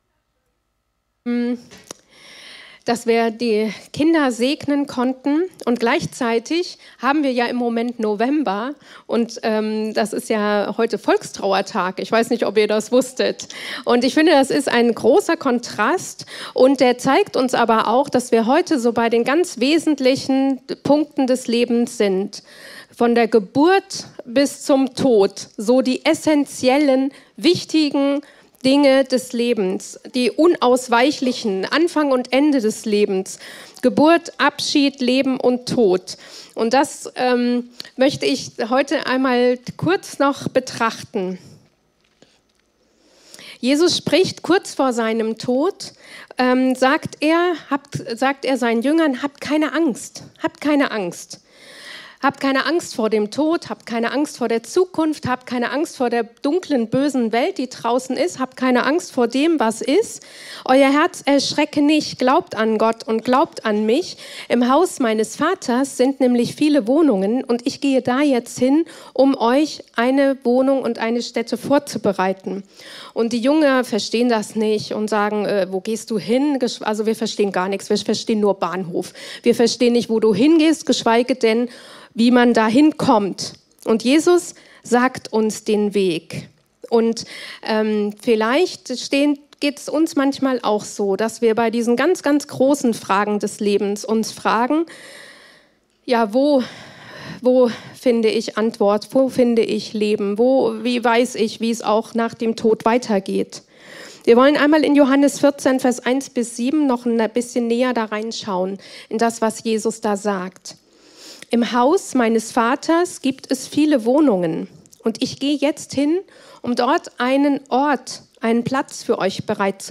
Predigten aus der freien Christengemeinde Die Brücke in Bad Kreuznach.